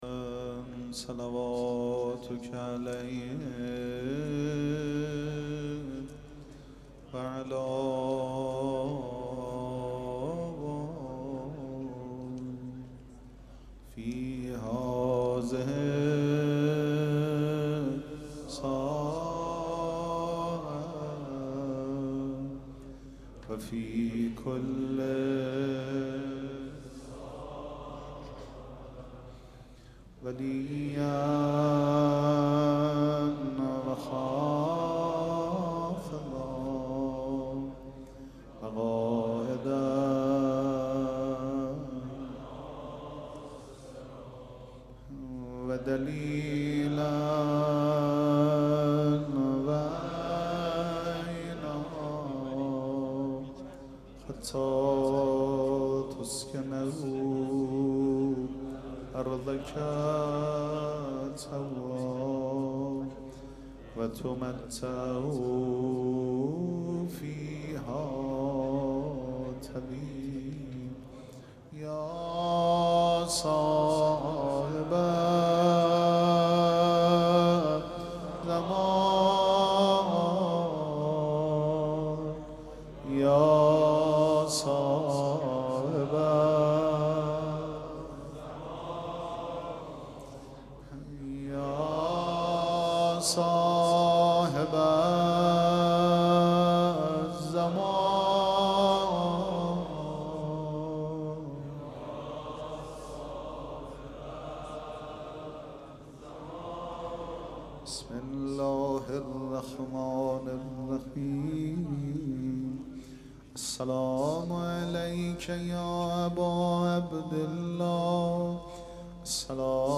مراسم عزاداری شب سوم محرم الحرام ۱۴۴۷
سبک اثــر پیش منبر